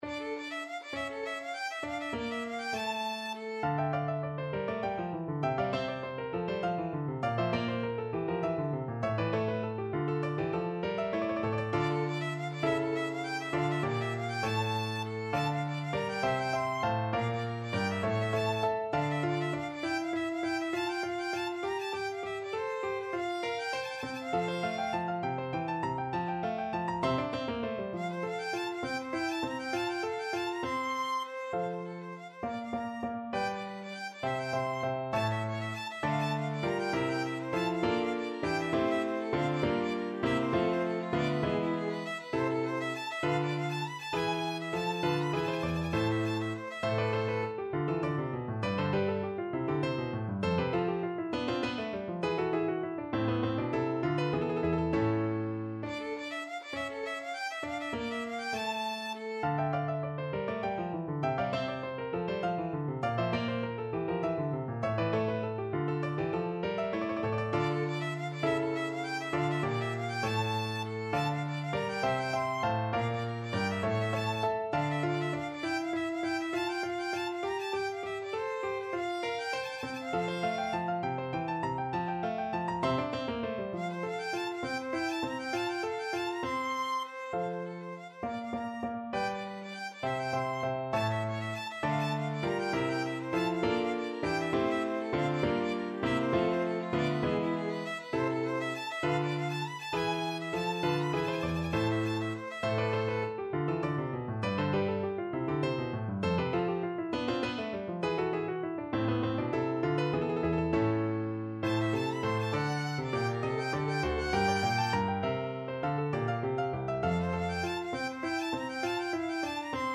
Violin
3/8 (View more 3/8 Music)
Presto (View more music marked Presto)
D minor (Sounding Pitch) (View more D minor Music for Violin )
Classical (View more Classical Violin Music)
marcello_concerto_3_VLN.mp3